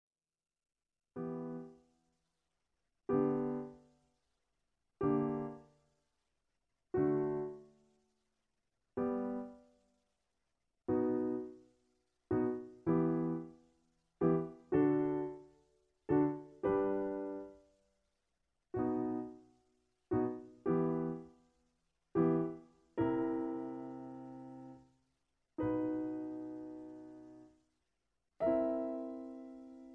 Original Key (E). Piano Accompaniemnt